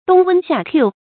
發音讀音